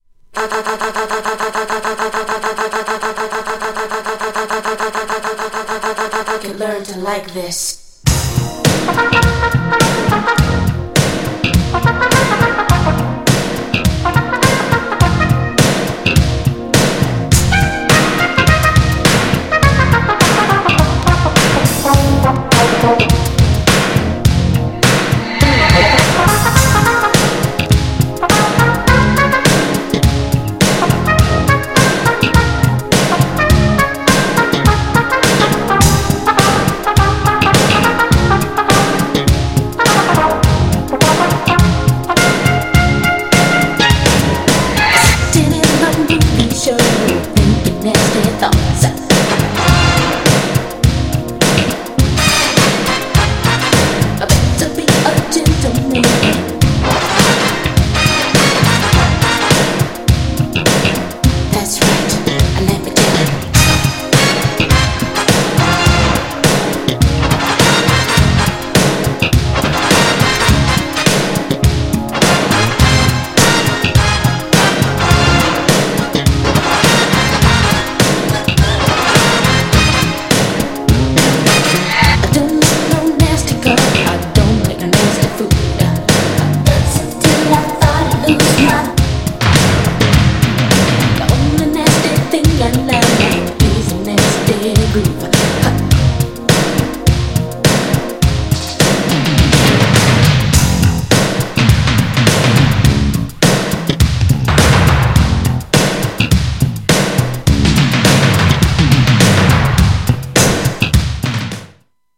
GENRE Dance Classic
BPM 86〜90BPM